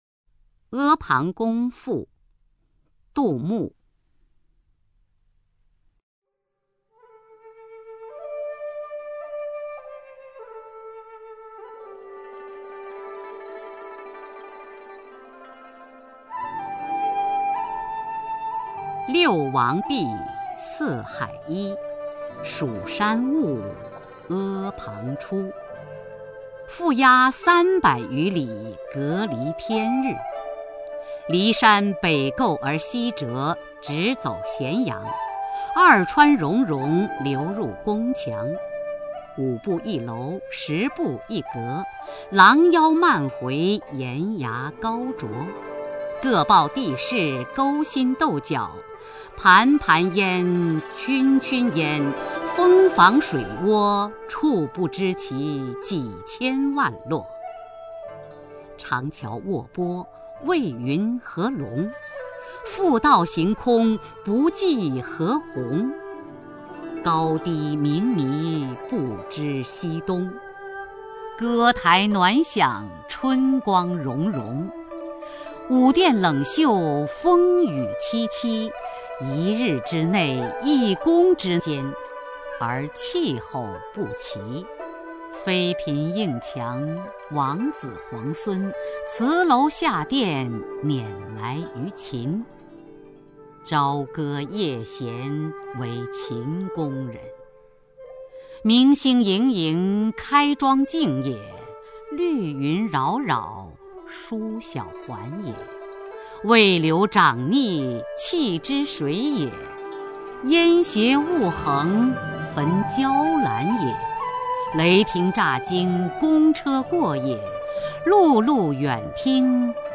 [31/10/2009]【以史为鉴】雅坤配乐朗诵杜牧 名作《阿房宫赋(e pang gong fu)》
朗诵 中央人民广播电台著名播音员 雅坤